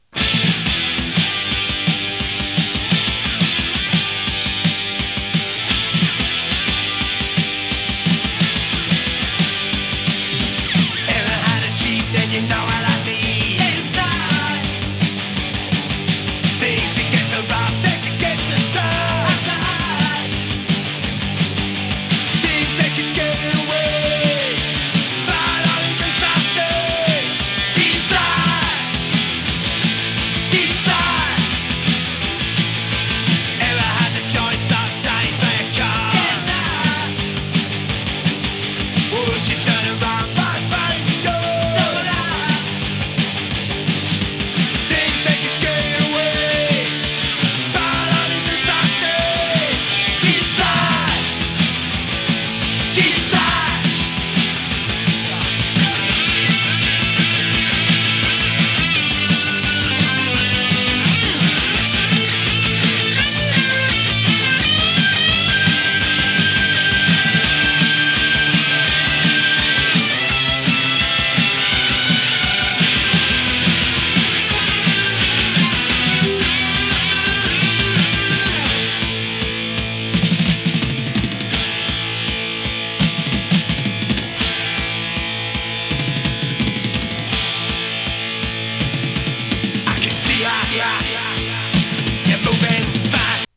疾走 初期パンク